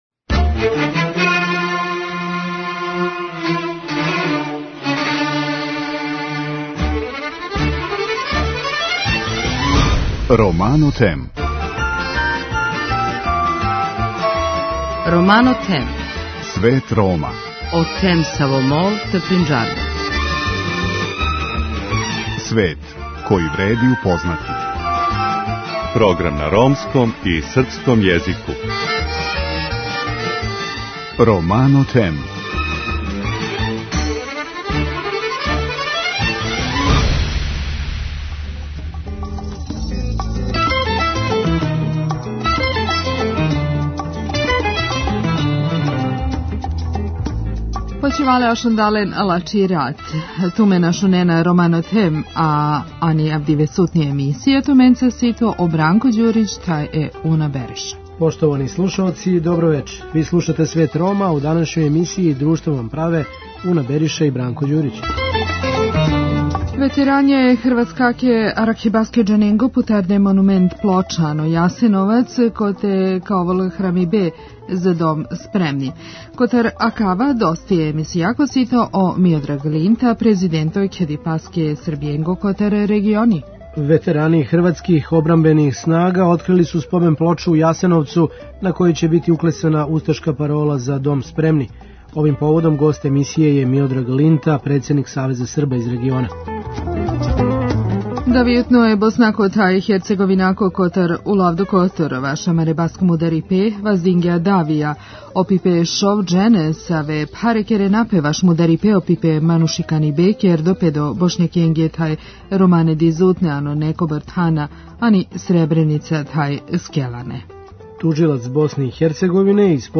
Овим поводом гост емисије је Миодраг Линта, председник Савеза Срба из региона.
преузми : 7.20 MB Romano Them Autor: Ромска редакција Емисија свакодневно доноси најважније вести из земље и света на ромском и српском језику.